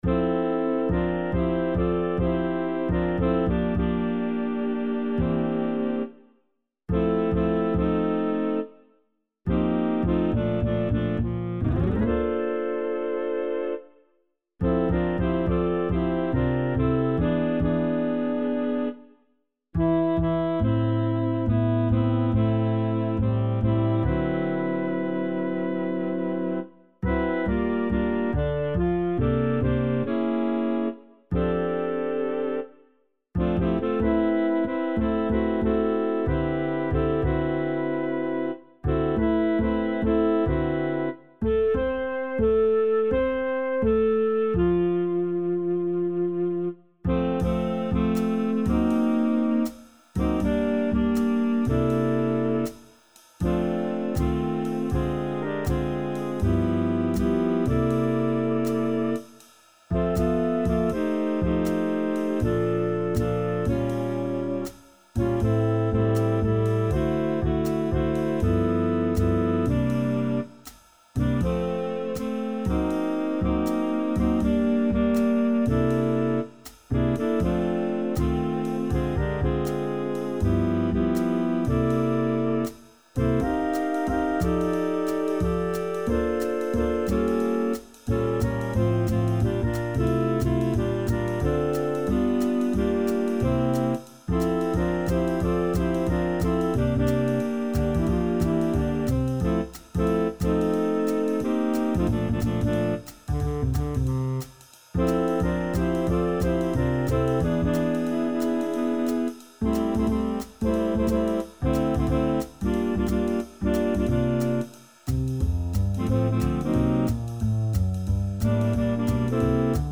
SATB | SSAA | TTBB | SSATB | SSAB | SATTB